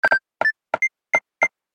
دانلود آهنگ کیبورد 20 از افکت صوتی اشیاء
دانلود صدای کیبورد 20 از ساعد نیوز با لینک مستقیم و کیفیت بالا
جلوه های صوتی